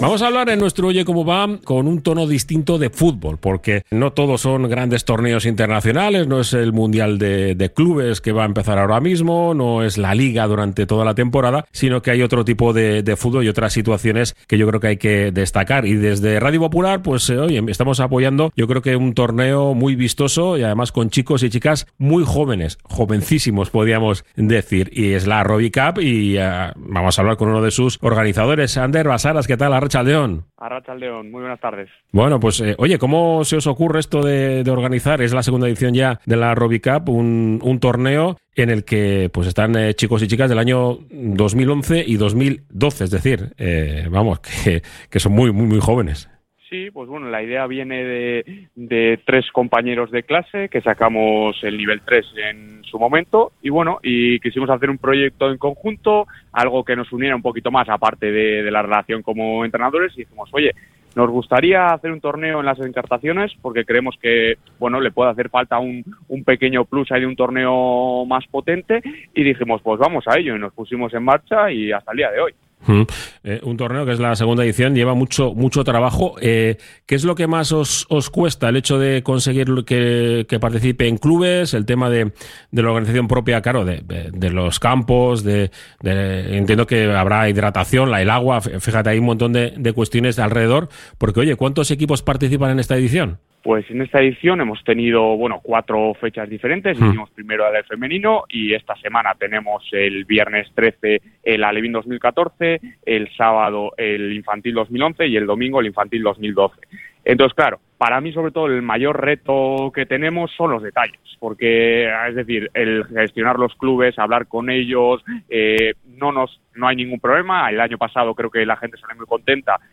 Radio Popular – Herri Irratia sigue siendo patrocinador del torneo con el objetivo de fomentar el deporte base y hemos conversado con los organizadores en el programa Oye Cómo Va, donde se han desvelado los pormenores de este torneo en Encartaciones.